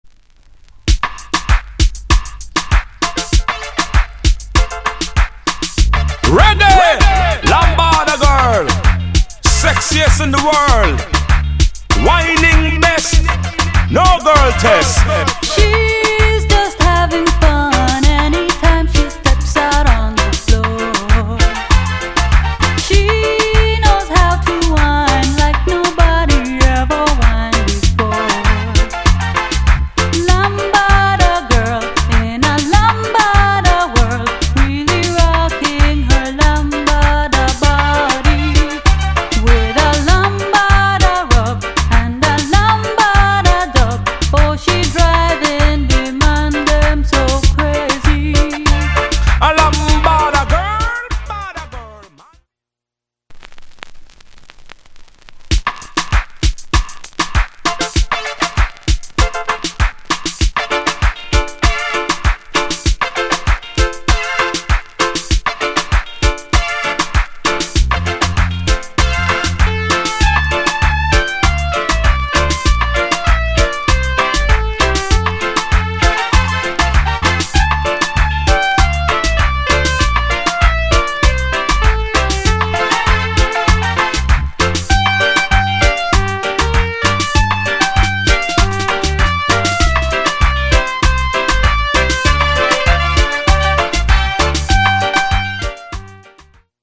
* '90 Female Vocal + Dee Jay Good Combi.